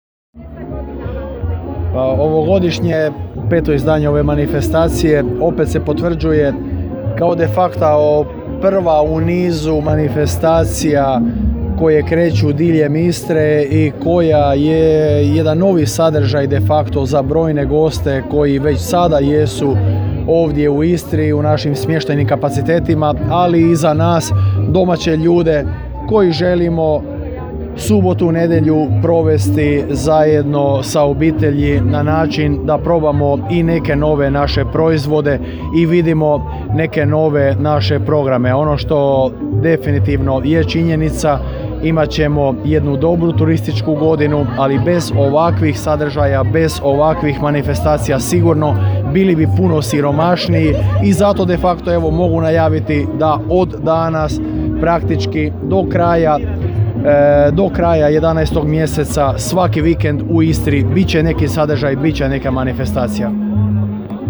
Župan Valter Flego - audio izjava